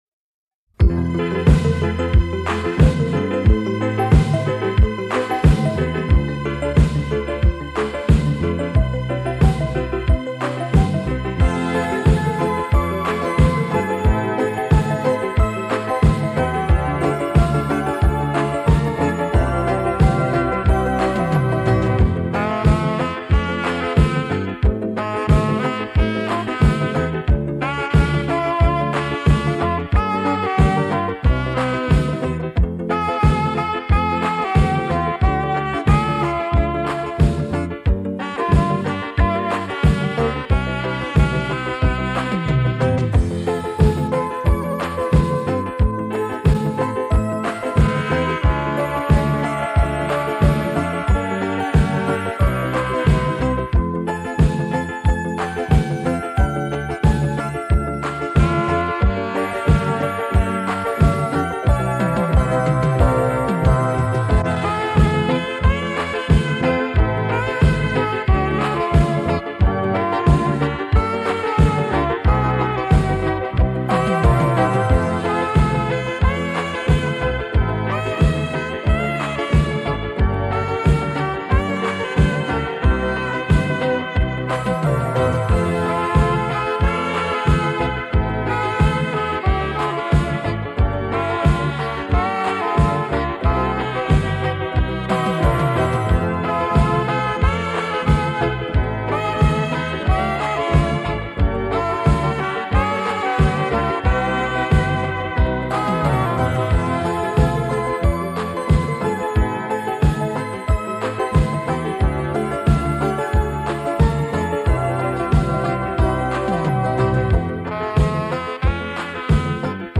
卡带转制320K/MP3
中四步---128K/MP3
很喜欢的舞曲音乐，谢谢分享